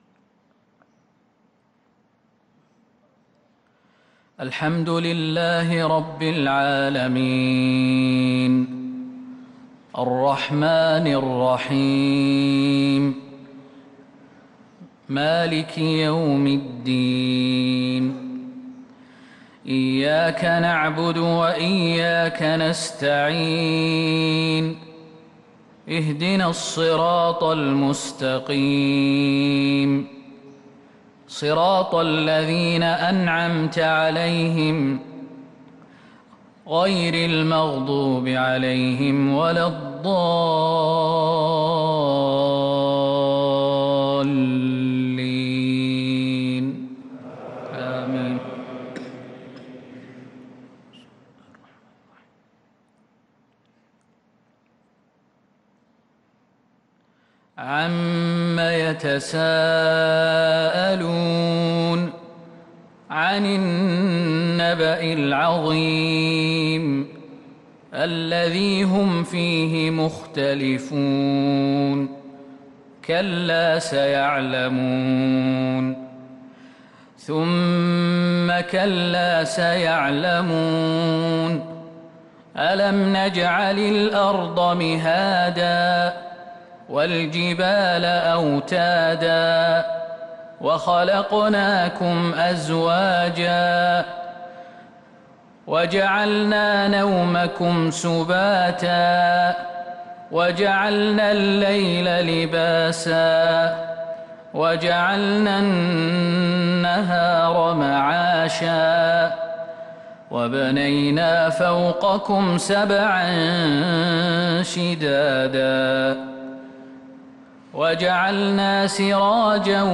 صلاة الفجر للقارئ خالد المهنا 20 رمضان 1443 هـ